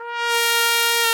Index of /90_sSampleCDs/Roland LCDP12 Solo Brass/BRS_Tpt _ menu/BRS_Tp _ menu